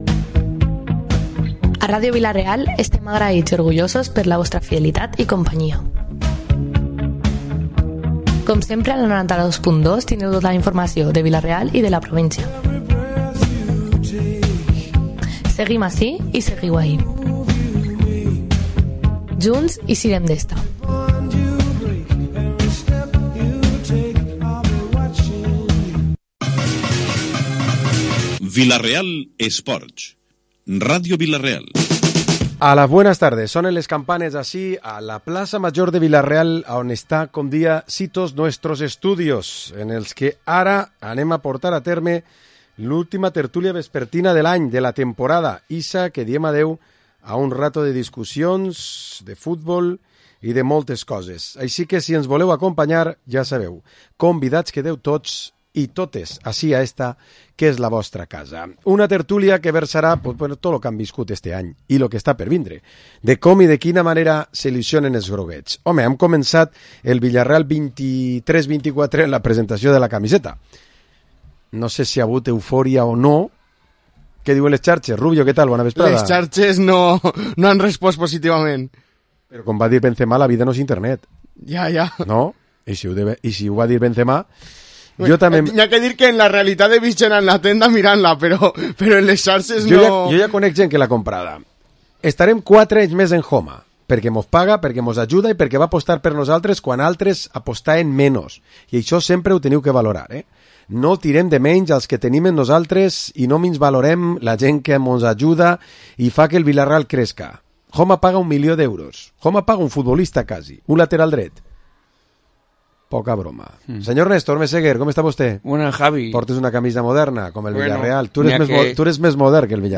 Programa esports dilluns tertúlia 12 de juny